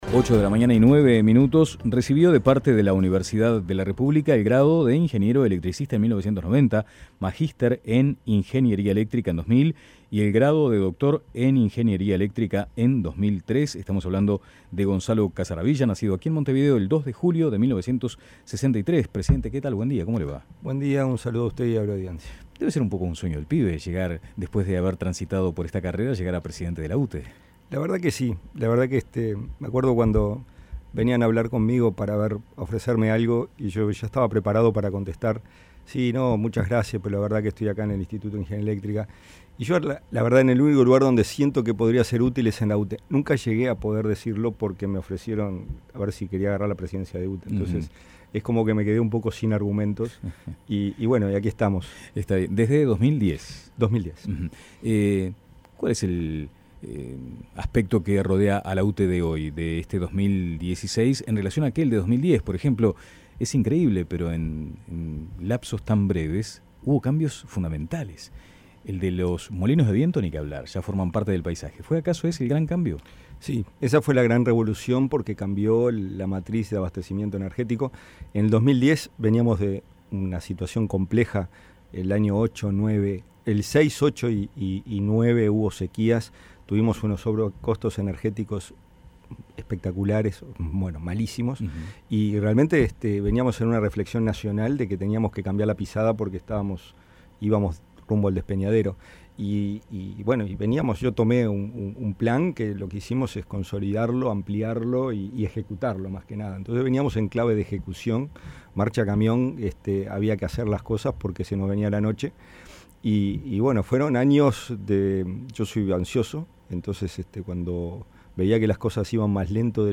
Entrevista a Gonzalo Casaravilla